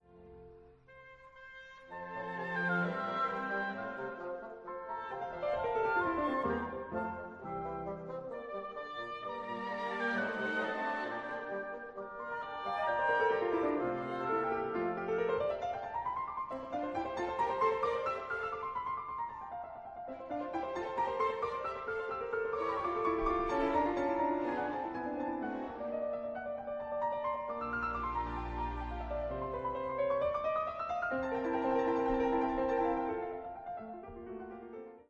piano
mozart-piano-concerto-20-excerpt.wav
In the first movement, the aggressive opening melody is delegated to the orchestra while the piano enters with gentle, more lyrical material.